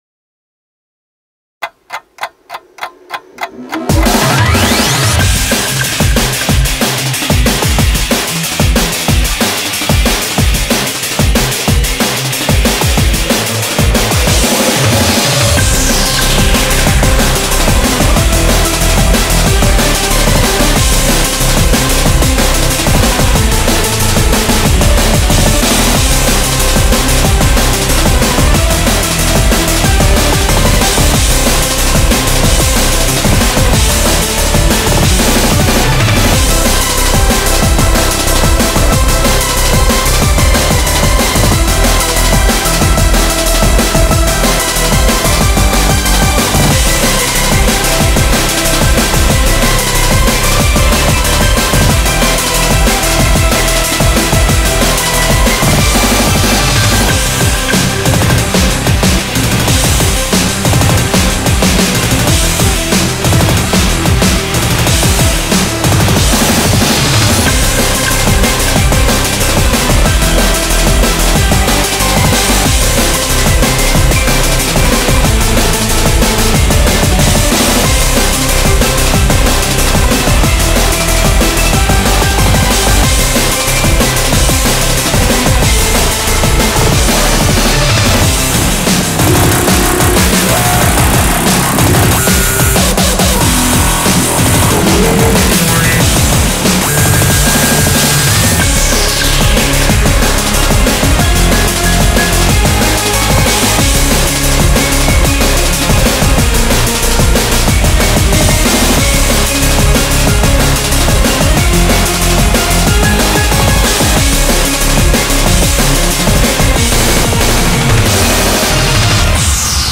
BPM185
Audio QualityPerfect (High Quality)
is a 185 BPM cyber D&B song that takes you to the future